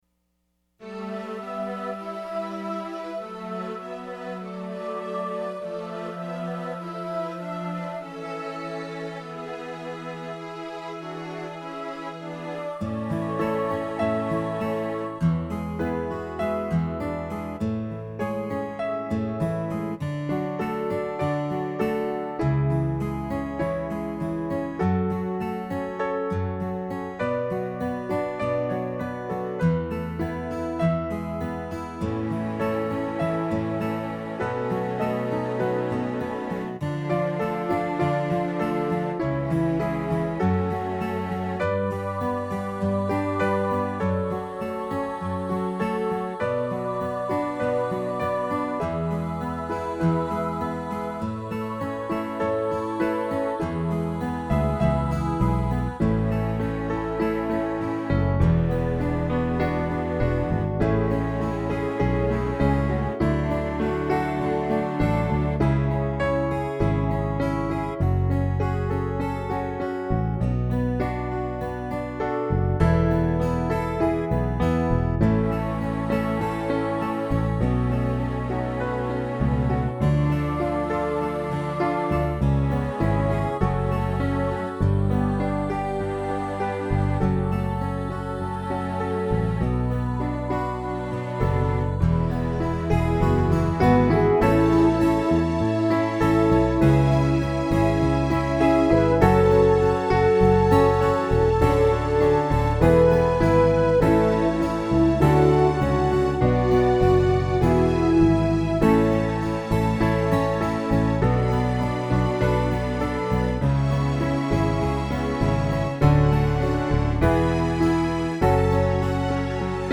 Audio link below to share the beauty of a song in progress:
A RAINBOW THROUGH MY TEARS – Midi recording
rainbow-through-my-tears-midi-in-progress.mp3